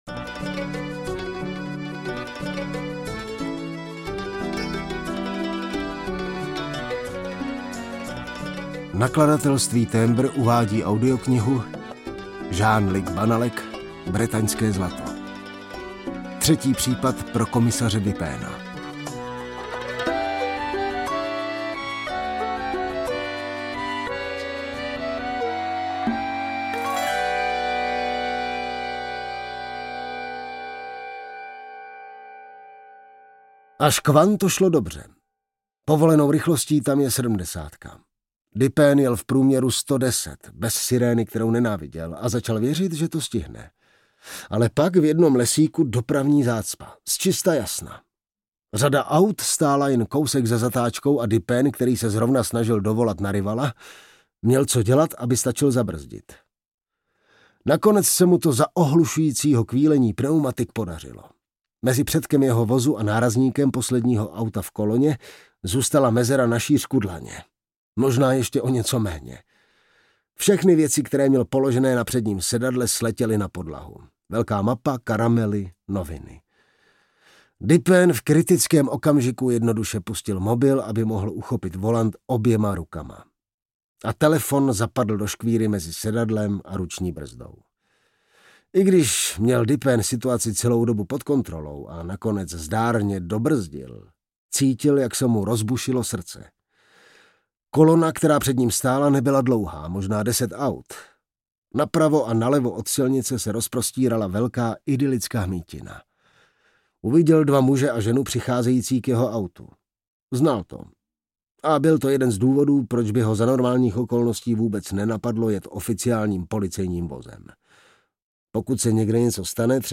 Bretaňské zlato audiokniha
Ukázka z knihy
bretanske-zlato-audiokniha